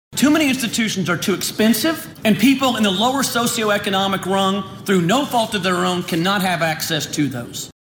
CLICK HERE to listen to commentary from OU President Joseph Harroz Junior.